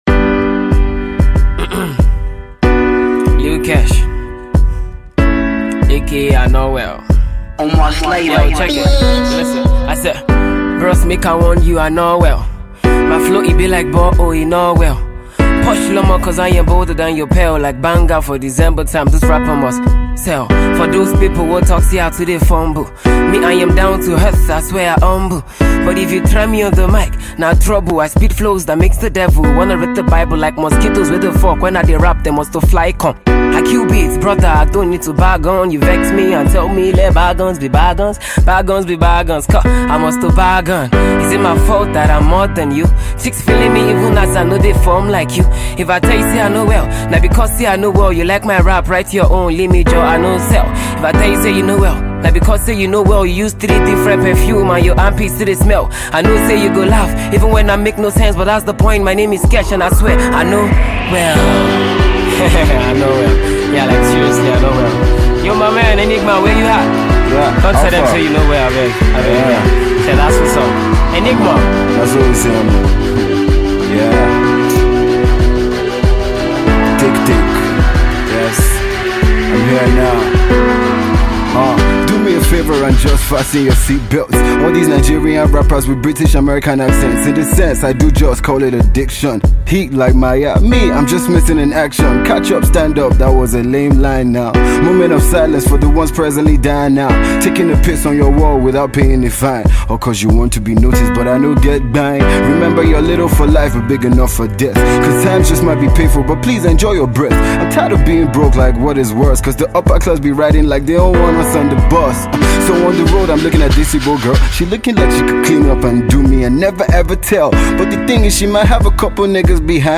This fresh freestyle